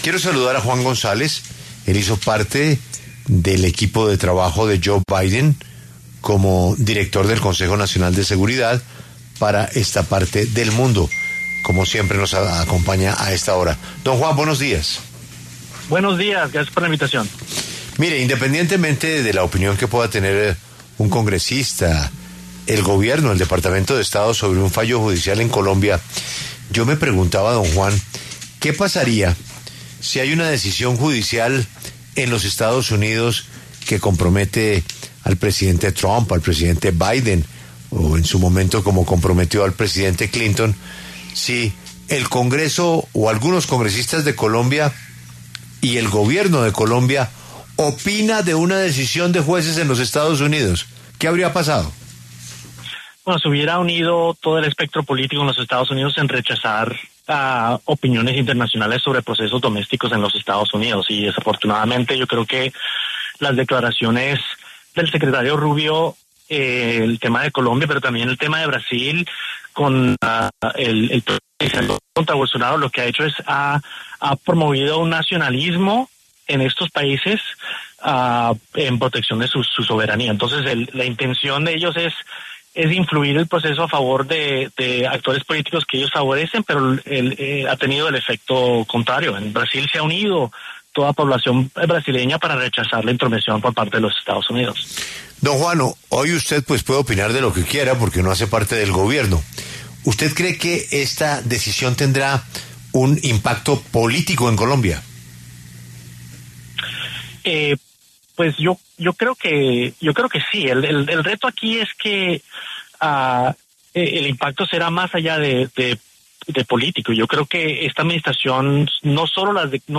Este martes, 29 de julio, habló en La W, con Julio Sánchez Cristo, Juan González, quien fue director principal del Consejo Nacional de Seguridad para el Hemisferio Occidental durante el gobierno de Joe Biden, y se refirió al fallo en contra del expresidente Álvaro Uribe y las declaraciones de Marco Rubio al respecto.